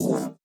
Horn Stab (1).wav